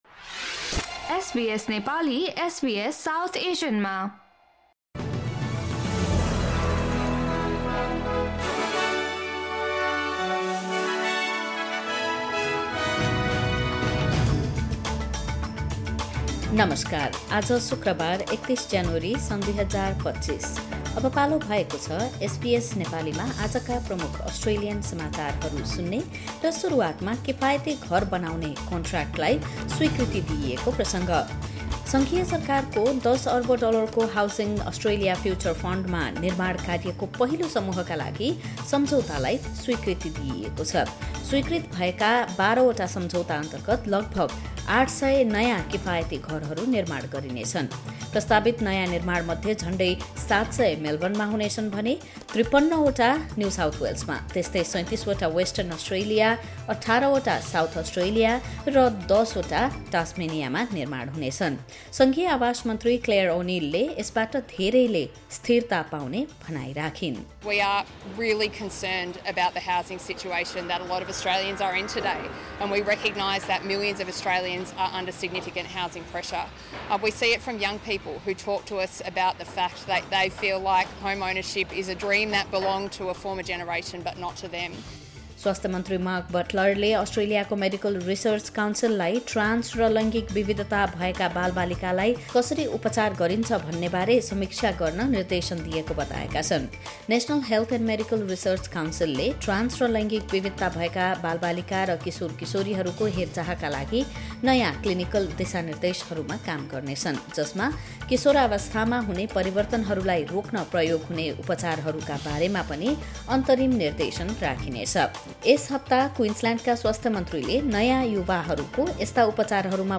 Listen to the latest top news from Australia in Nepali.